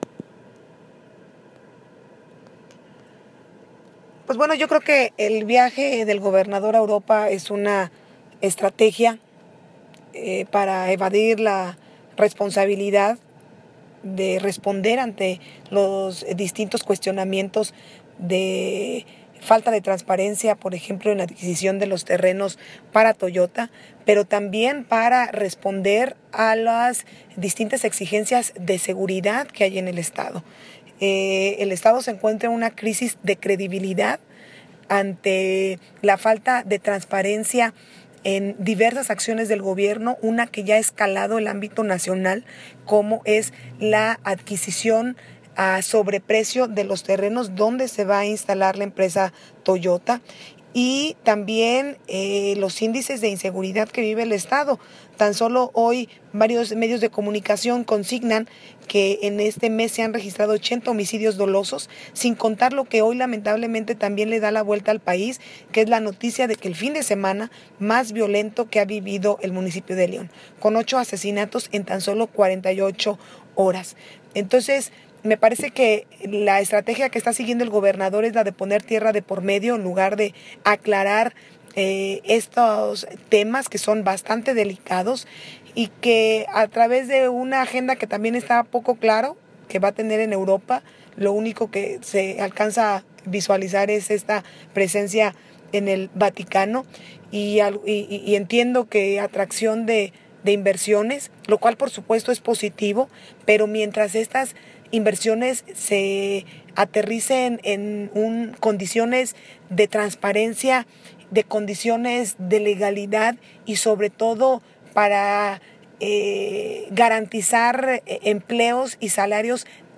Yulma Rocha Aguilar Diputada Federal PRI
ADUIO-DIP.-FED.-YULMA-ROCHA.m4a